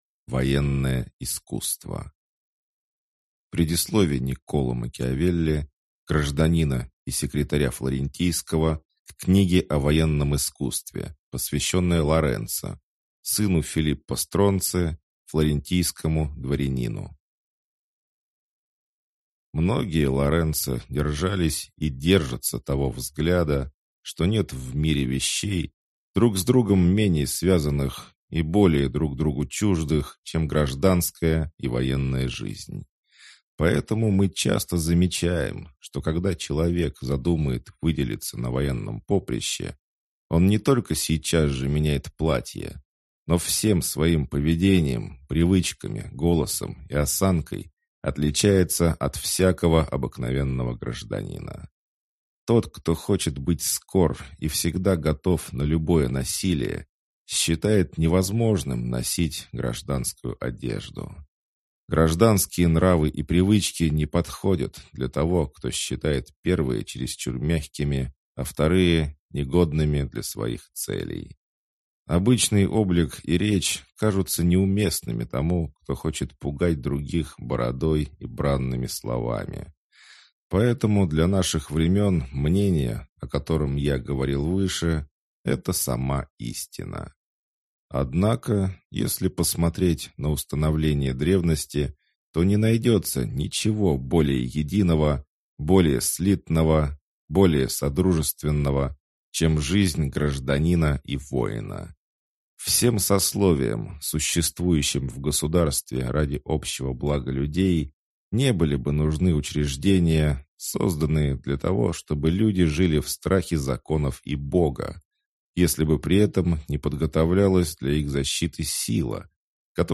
Аудиокнига О военном искусстве | Библиотека аудиокниг